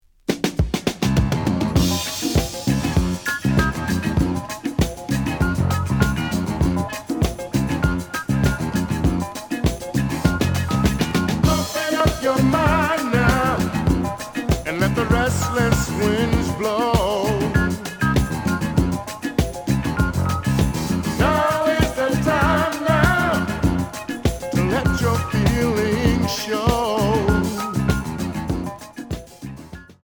The audio sample is recorded from the actual item.
●Genre: Disco
A side plays good.